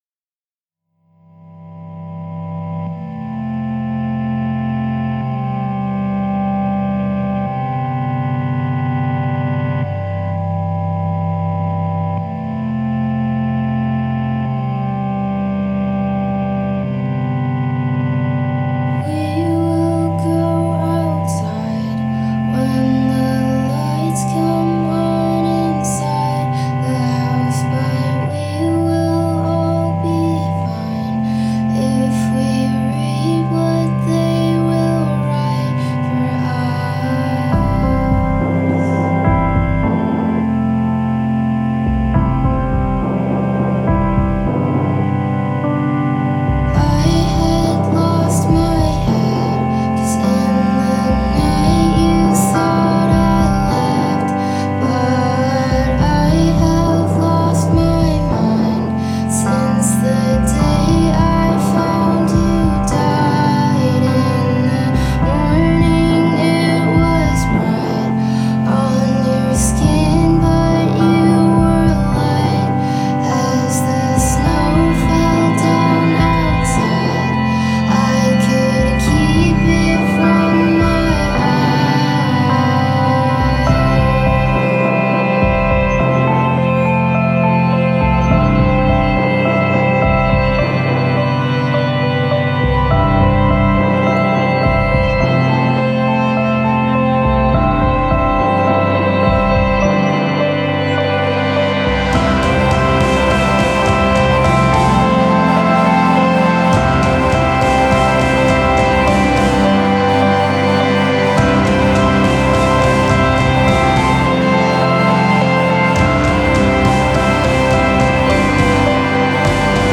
vocals, guitar, keyboard
drums, backing vocals
bass